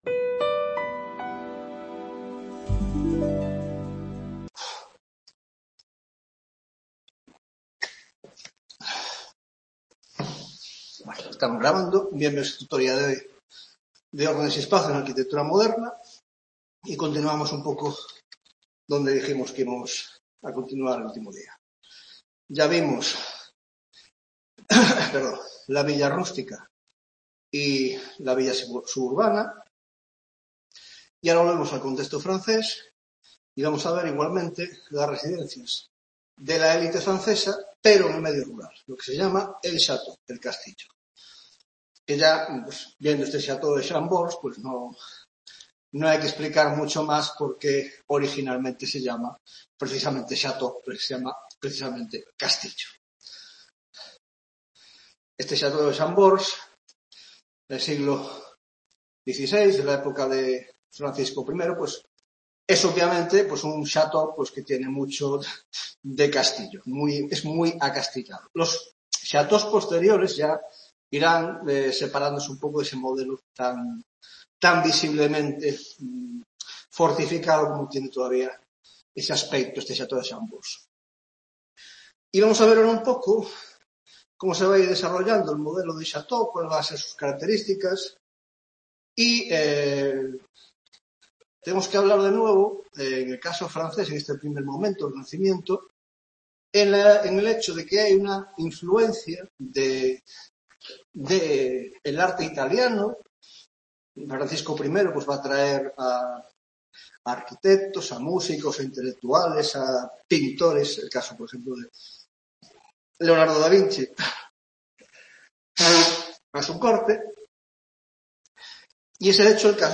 8ª Tutoría de Órdenes y Espacio en la Arquitectura Moderna (grado de Historia del Arte): Tipología: 1) El Château francés y Los Palacios Reales; 2) El Jardín, 3) Otras tipologías: la Biblioteca, el Hospital y el Teatro.